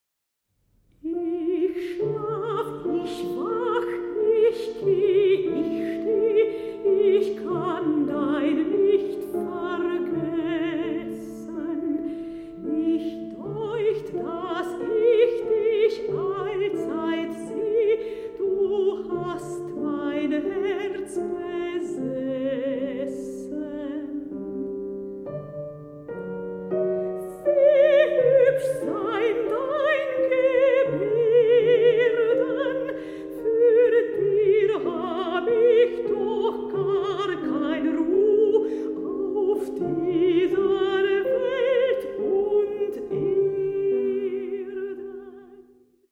Sopran
Klavier